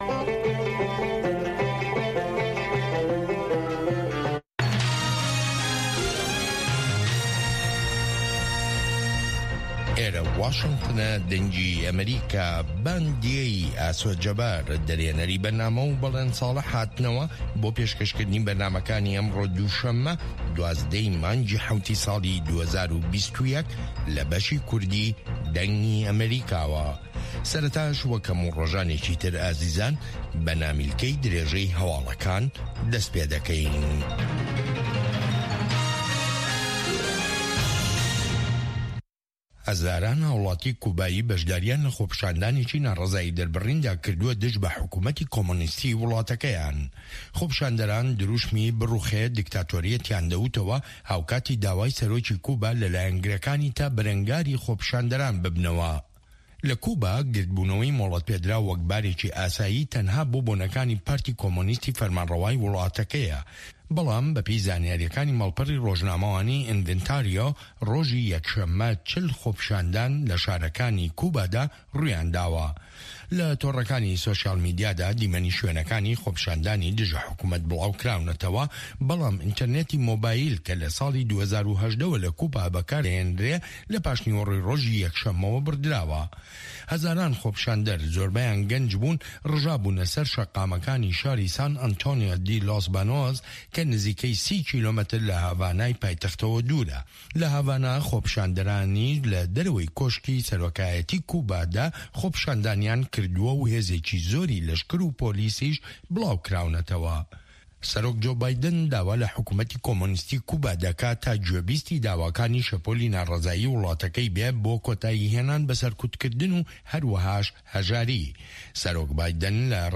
Nûçeyên 3’yê paşnîvro
Nûçeyên Cîhanê ji Dengê Amerîka